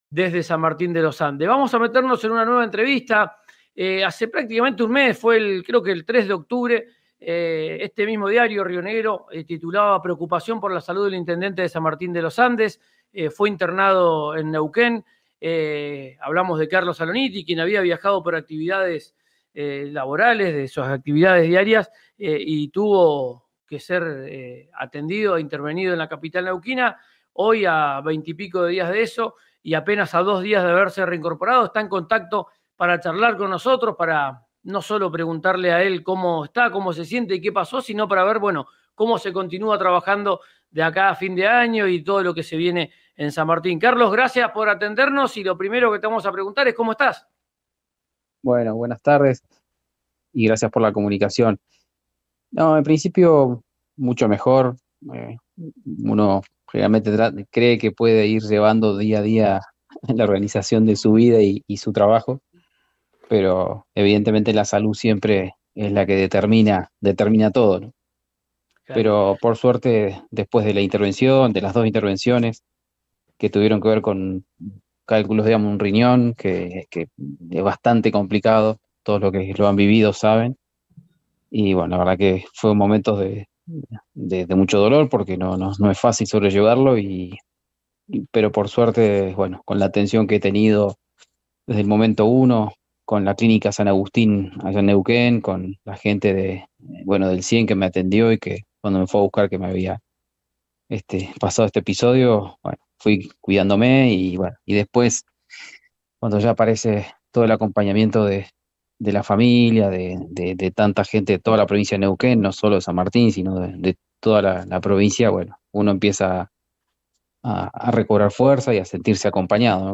El intendente de San Martín de los Andes se reincorporó a sus funciones después de someterse a dos intervenciones quirúrgicas de urgencia en Neuquén capital. En su primera entrevista tras el alta, reflexionó sobre su ritmo de vida y el trabajo.